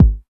KIK XC.KIK01.wav